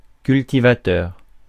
Ääntäminen
IPA: [kyl.ti.va.tœʁ]